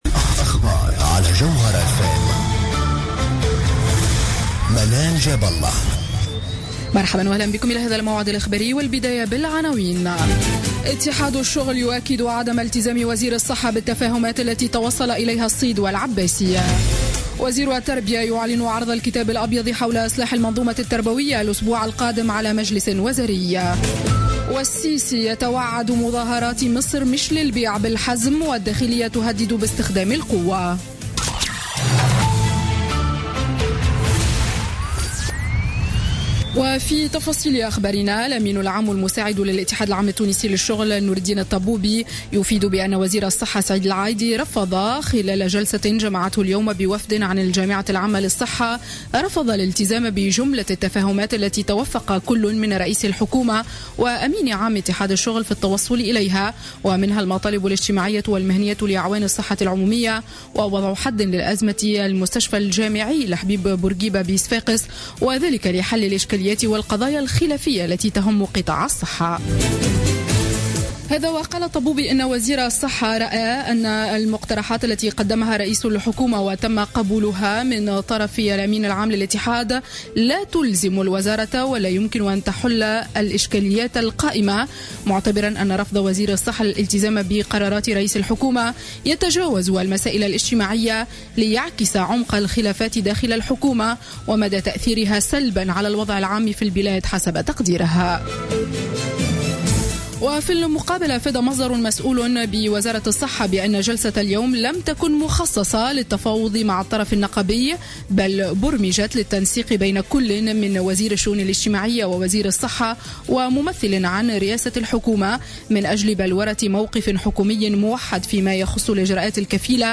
نشرة أخبار السابعة مساء ليوم الأحد 24 أفريل 2016